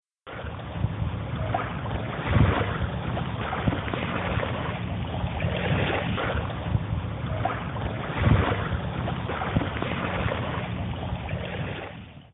Water lapping at Sandgate's shore, Brisbane